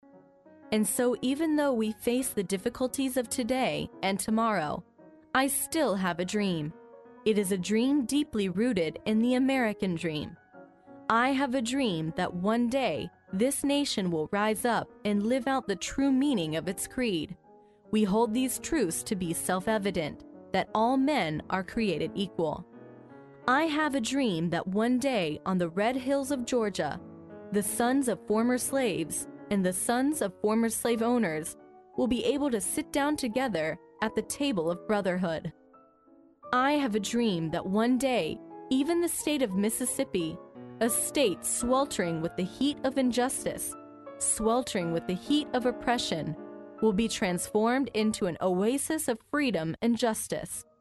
在线英语听力室历史英雄名人演讲 第27期:我有一个梦想(1)的听力文件下载, 《历史英雄名人演讲》栏目收录了国家领袖、政治人物、商界精英和作家记者艺人在重大场合的演讲，展现了伟人、精英的睿智。